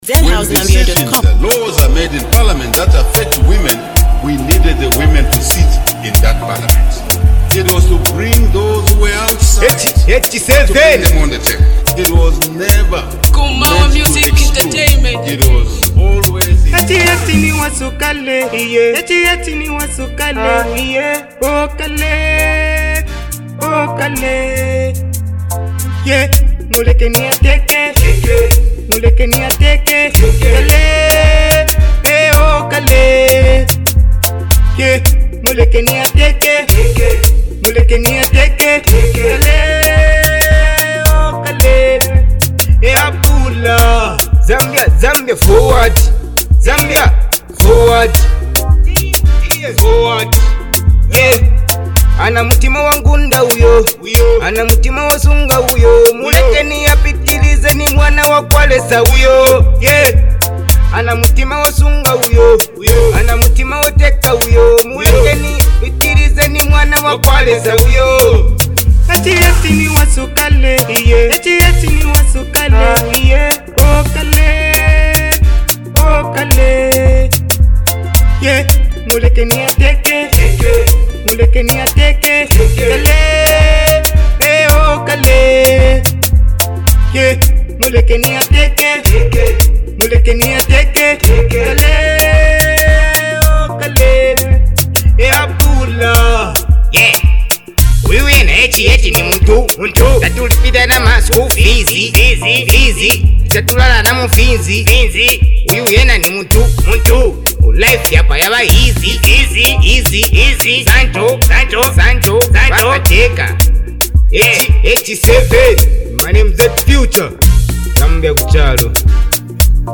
Through uplifting lyrics and strong delivery